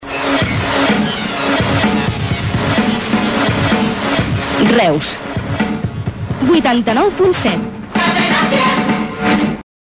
Identificació de l'emissora i freqüència